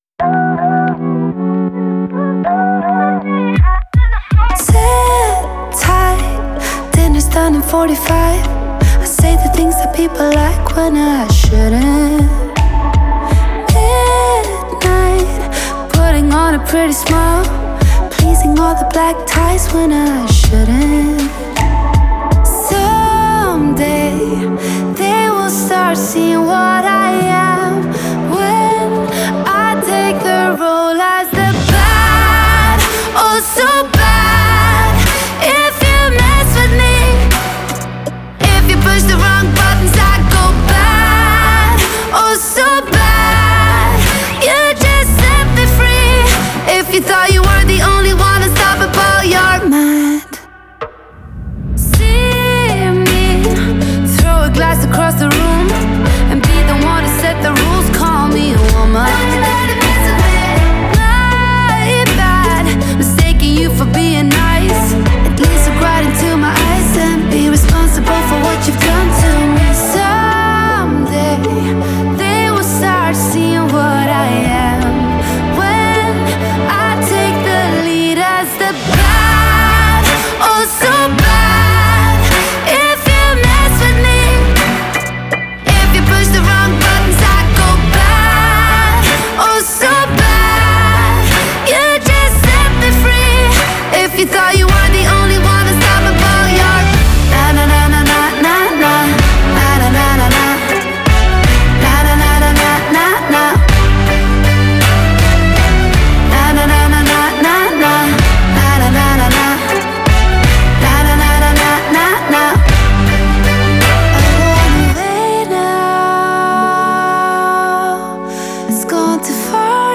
это яркий трек в жанре поп с элементами электронной музыки